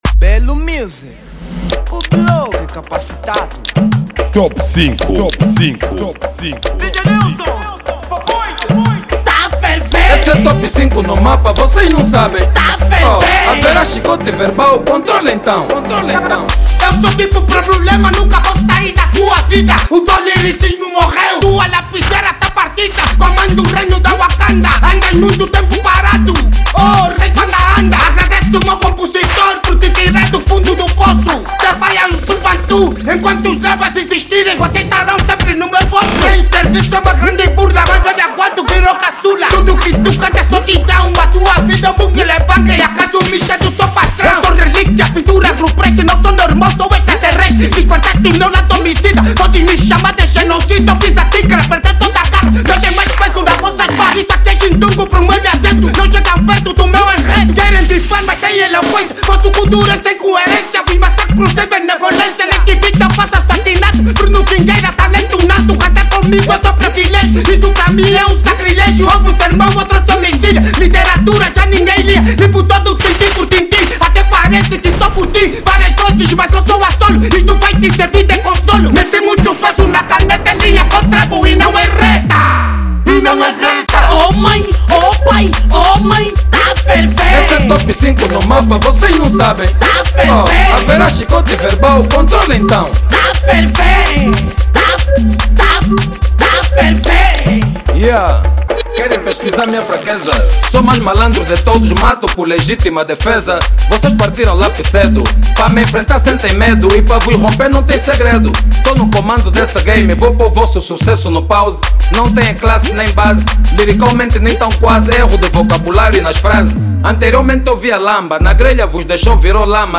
Género: Kuduro